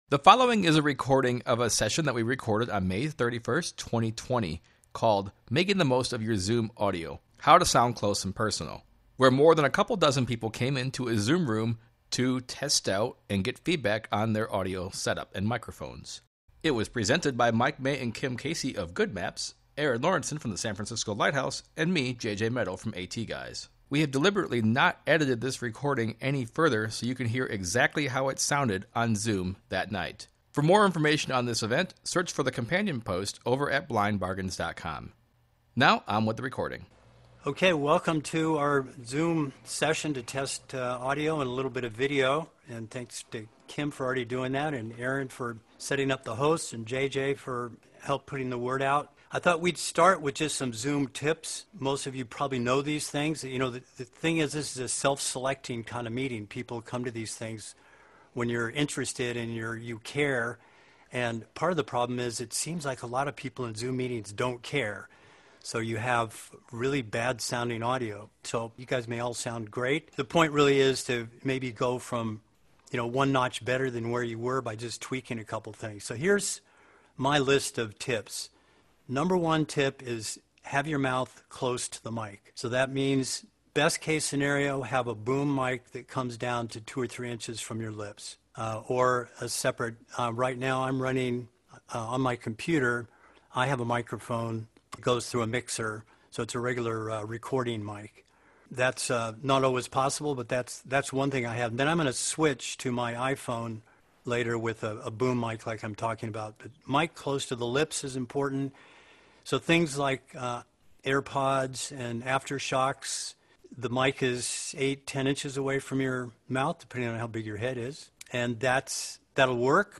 We heard a range of headsets, microphones, and other setups at a variety of price points, and gave feedback on everyone's sound along with tips for using Zoom and other chat systems effectively.